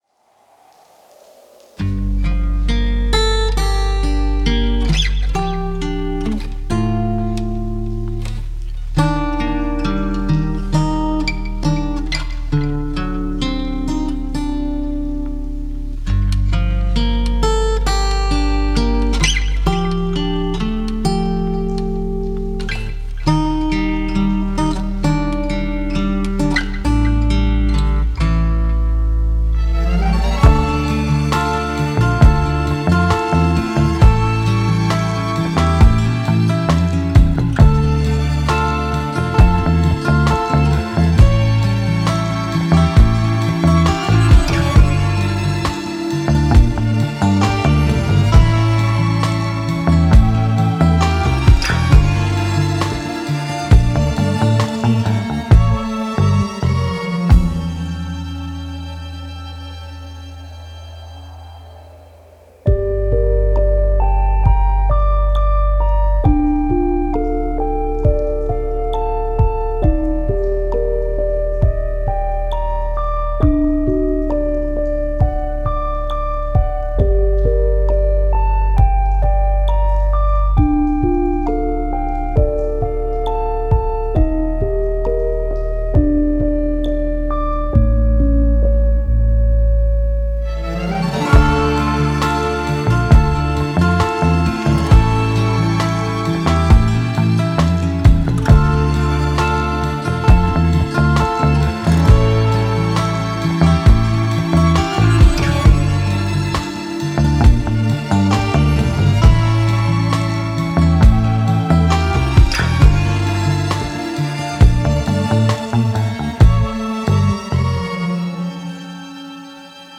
The presentation of Rives offered visitors a fully immersive experience, where visual perception is complemented by a bespoke soundscape.
The result is a creation unfolding in sequences and layers that expresses, far more intimately than words, its deeper nature and the emotions it evokes.
Here, piano notes shimmer like the sequins of the embroidery; there, movements of strings echo the ebb and flow of the drawing; elsewhere, the sounds of a synthesiser resonate with the strata and lines of The Leaves…